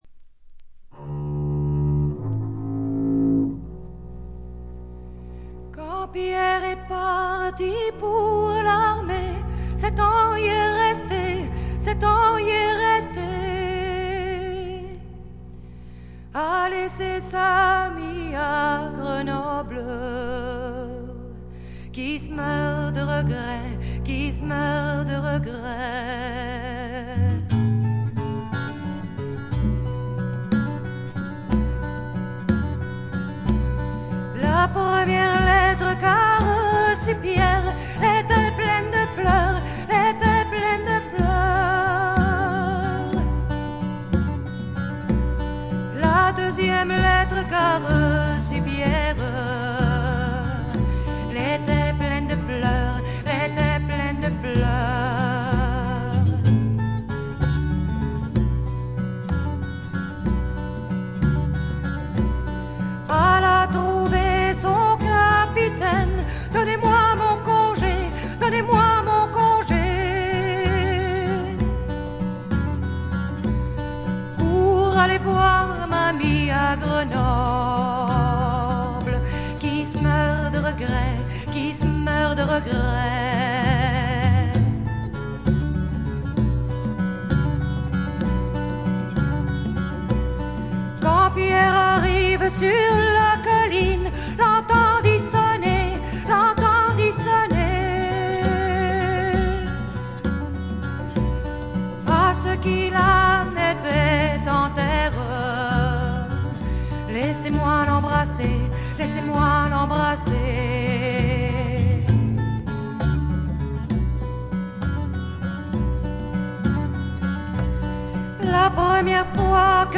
静音部の微細なチリプチのみでほとんどノイズ感無し。
フランスの女性フォーク・シンガー。
試聴曲は現品からの取り込み音源です。
Vocals
Guitar, Autoharp, Harmonica, Flageolet
Double Bass
Percussion, Violin
Recorded At - Studio D'Auteuil